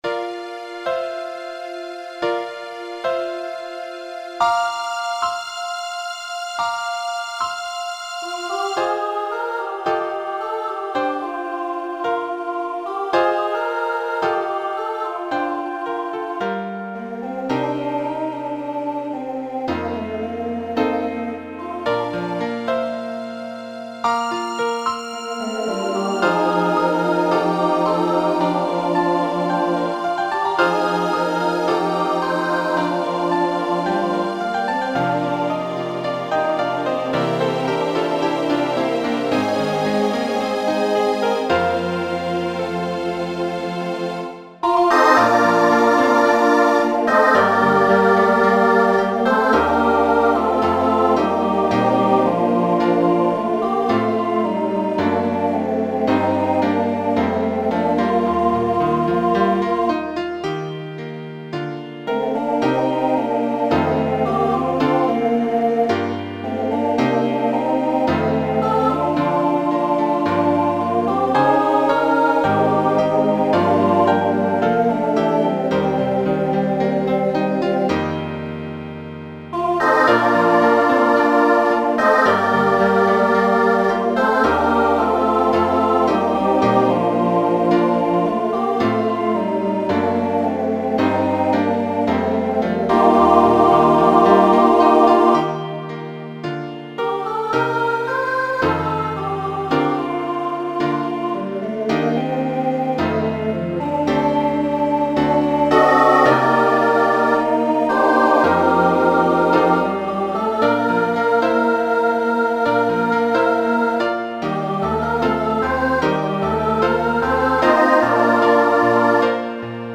Voicing SATB Instrumental combo Genre Broadway/Film
Show Function Ballad